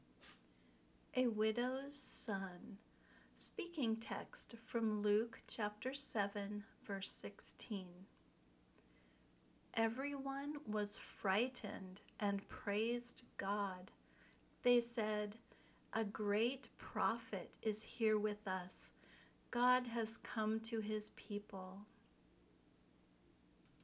Try to imitate the speaker’s intonation, the rhythm of her speech, and the stresses she puts on words and syllables.
Notice the silent “gh” in “frightened.” The “gh” is not pronounced. Also notice the “ph” that is pronounced like an “f” in the word “prophet.”